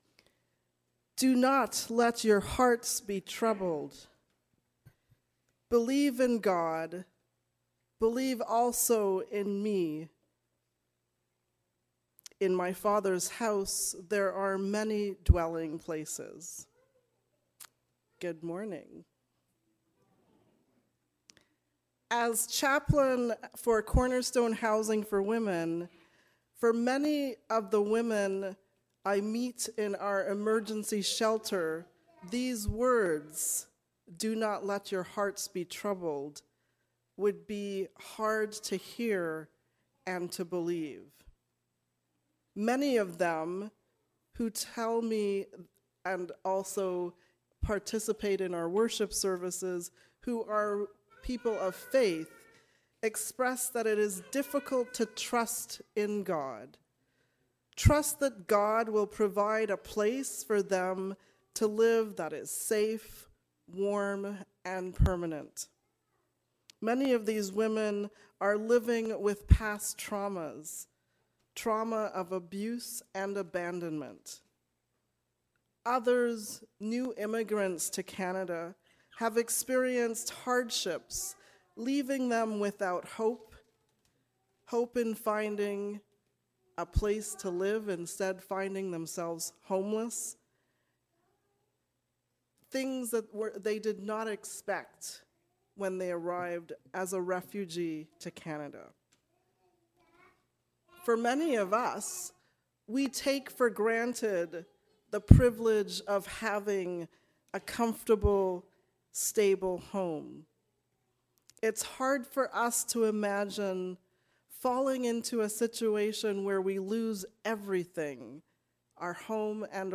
Do not let your hearts be troubled. A sermon on John 14:1-14